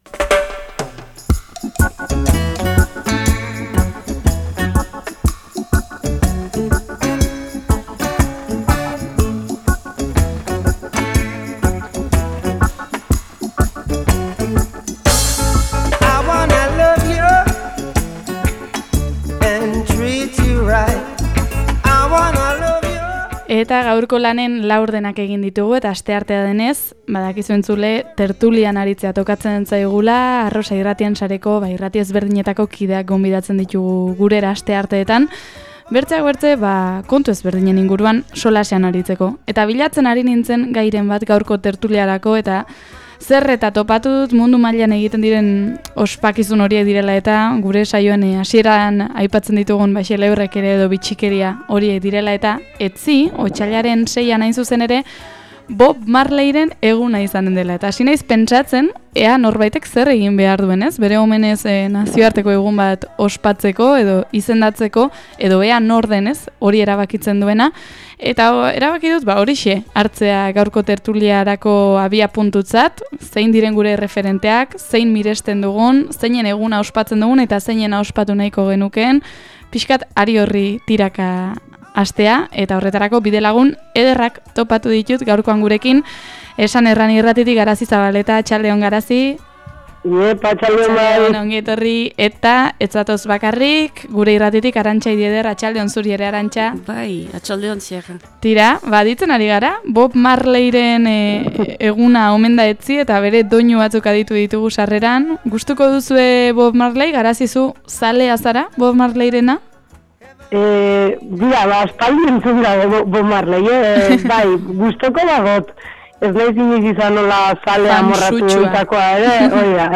Asteartero gisan Arrosa irratien sareko lankideekin tertulian aritzeko aukera izan dugu.